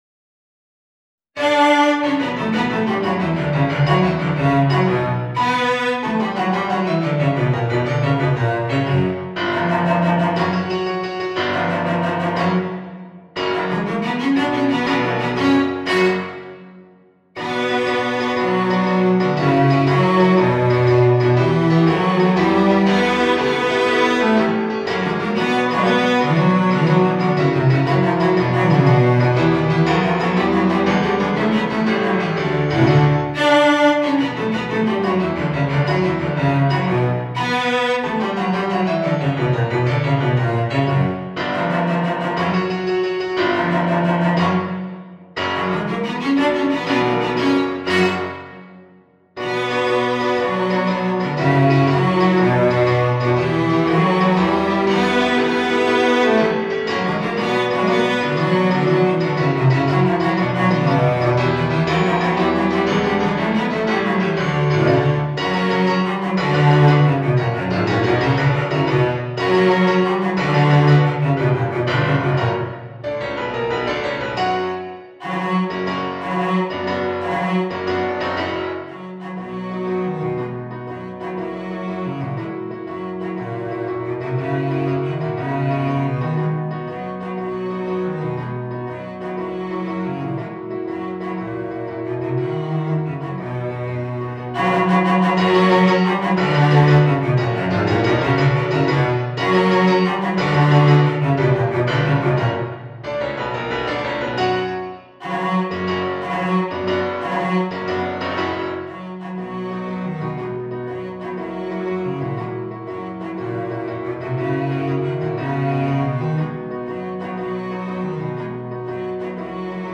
チェロ+ピアノ